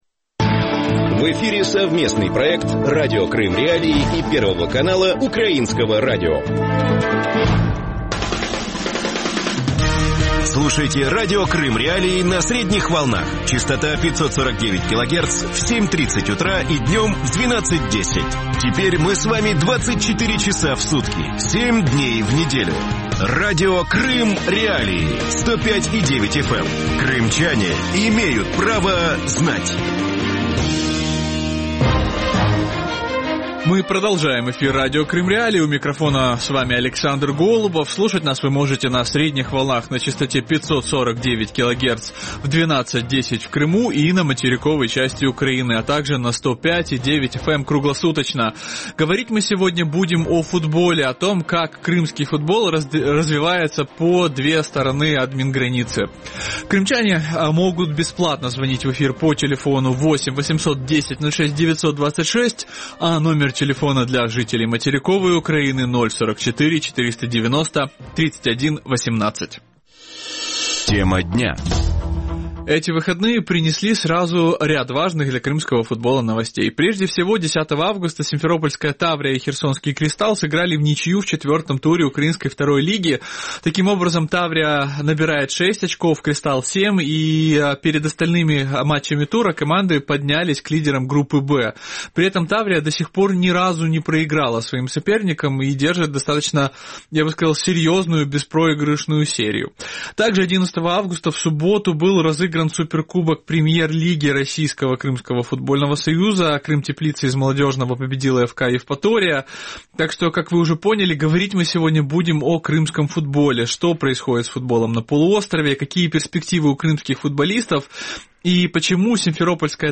Почему симферопольская «Таврия» переехала на материк? Есть ли у крымского футбола шанс на выход на международный уровень? Ответы на эти вопросы ищите с 12:10 до 12:40 в эфире ток-шоу Радио Крым.Реалии